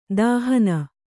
♪ dāhana